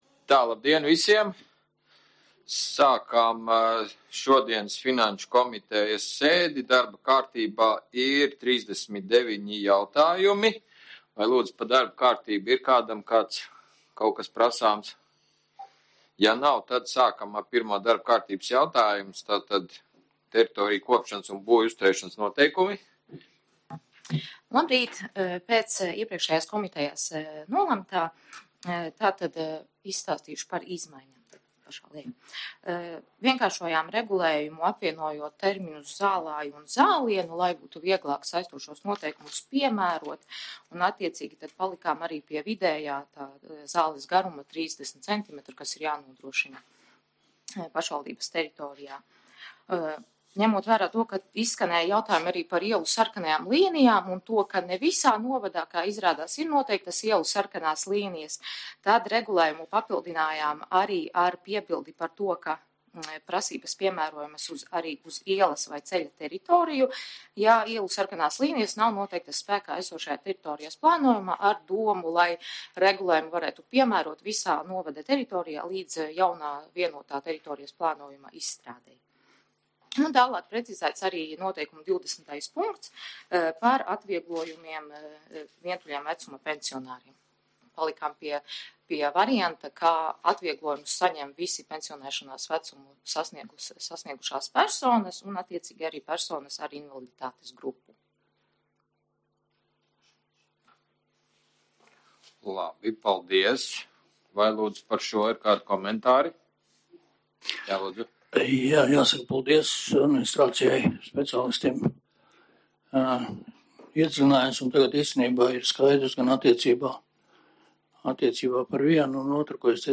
Valmieras novada pašvaldības domes Finanšu komitejas sēde 20.04.2023.
Valmieras novada pašvaldības domes Finanšu komitejas sēde notiks ceturtdien, 2023.gada 20.aprīlī, plkst 10.00 Raiņa ielā 14 (3.stāvā, 301.telpā), Valmierā, Valmieras novadā